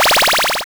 Magic3.wav